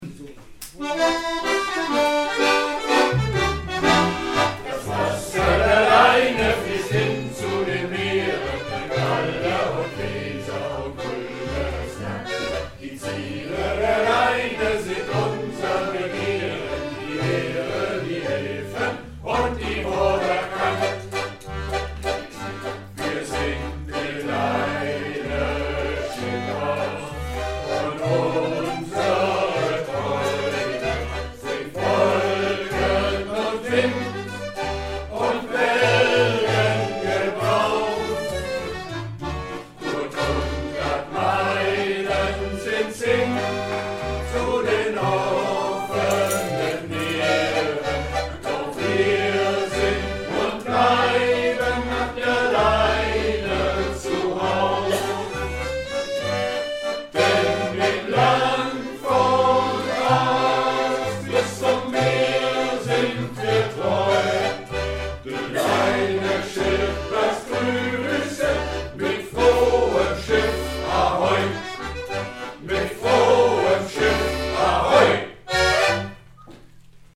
Shantys
Chor Chorgesang Jubiäum